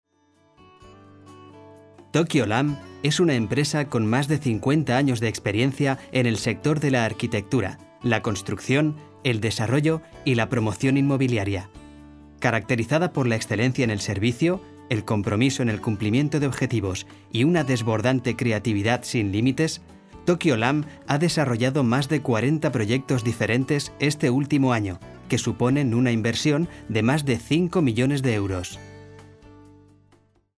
Tengo una voz joven, educada y aplicable en multitud de estilos, como publicidad, documentales, audiolibros,... Especial habilidad para interpretar personajes con voz fuera de lo común. Amplia experiencia en "listenings" de cursos de español.
kastilisch
Sprechprobe: Industrie (Muttersprache):